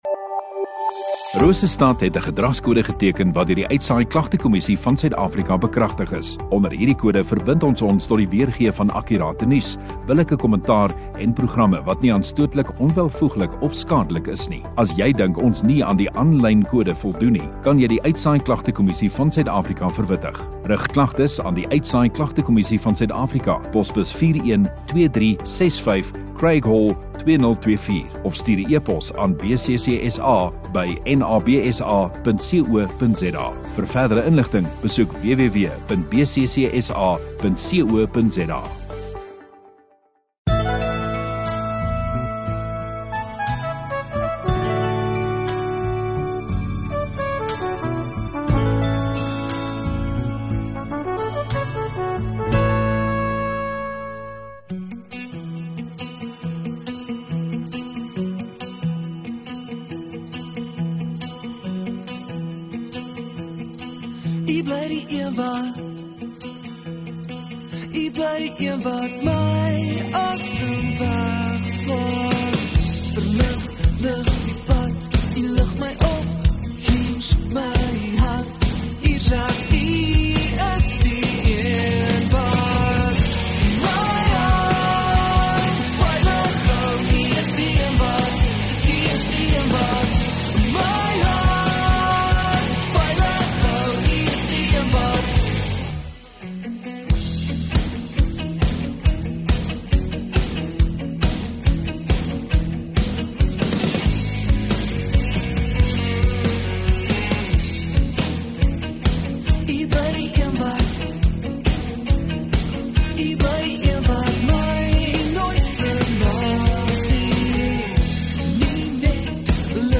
7 Dec Saterdag Oggenddiens